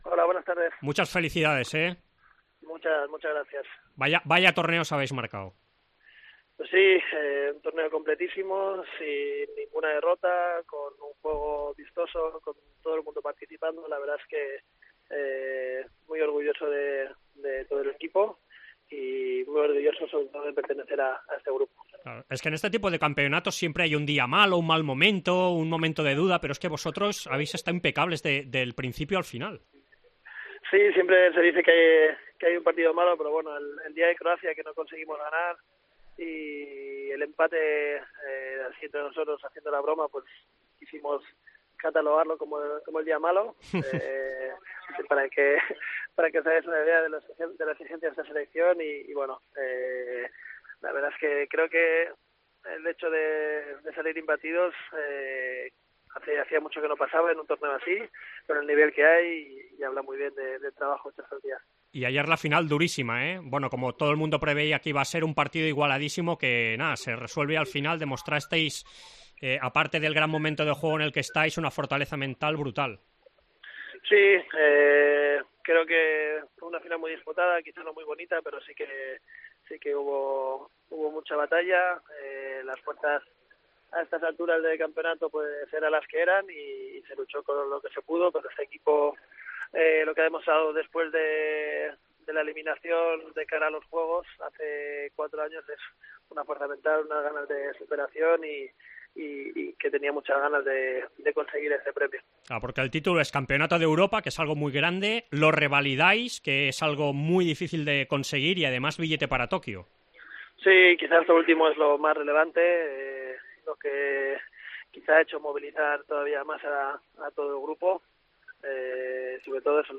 Entrevista a Gonzalo Pérez de Vargas